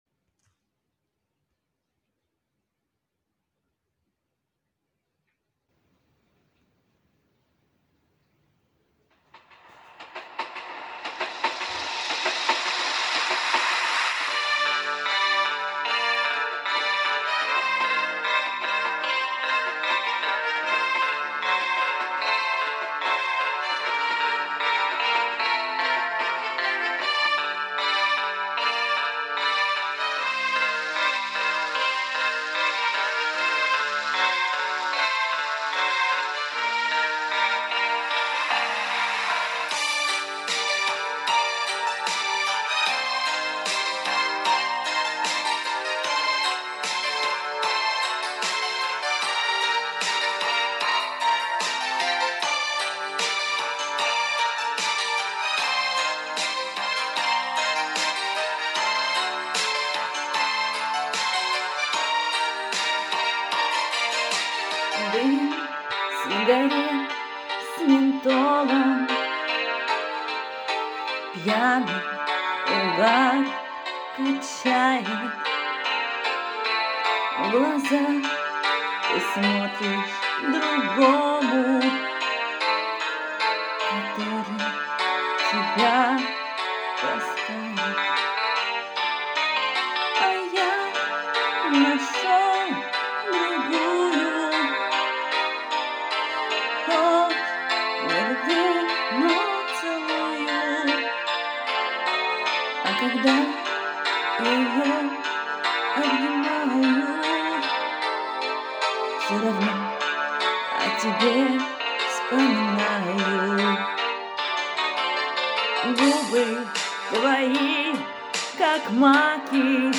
голос такой классный и поёшь с ног сшибательно***